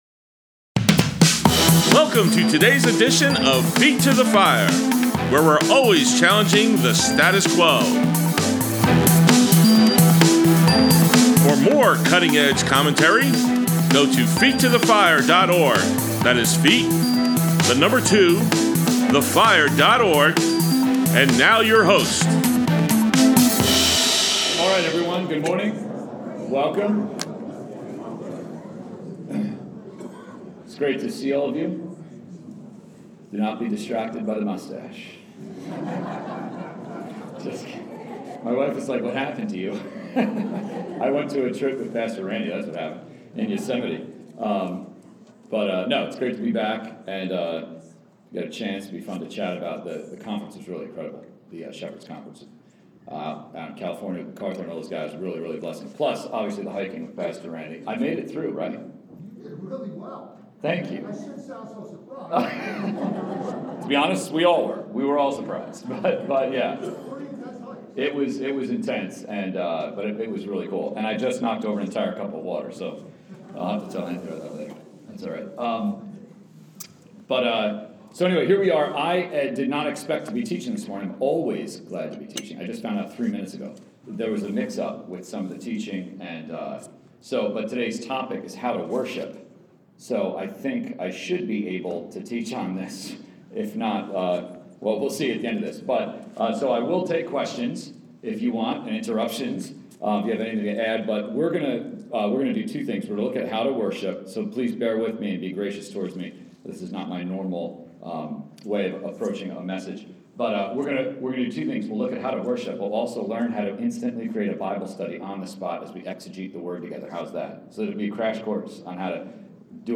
Adult Sunday school, Grace Bible Church, March 13, 2022